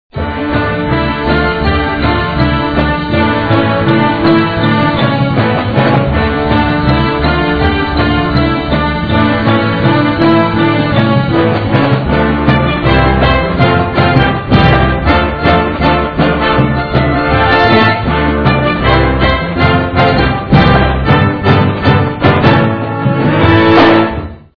Gattung: Für Marching Band
Besetzung: Blasorchester